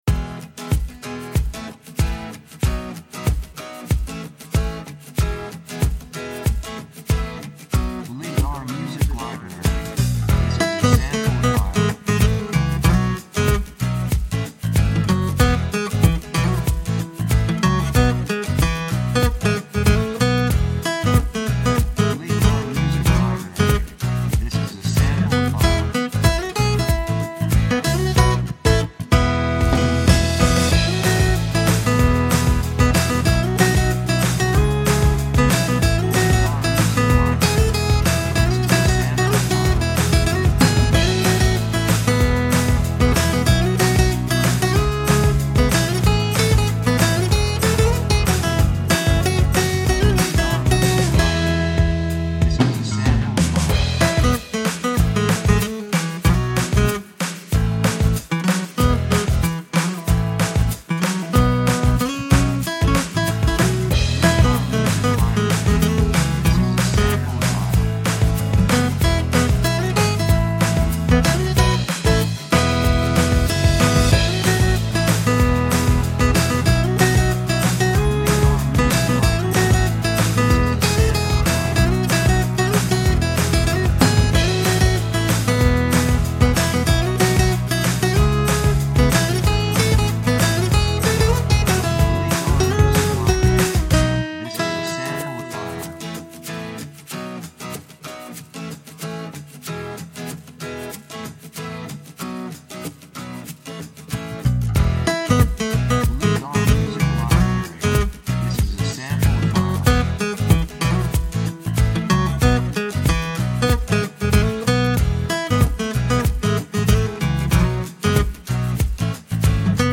3:03 94 プロモ, アコースティック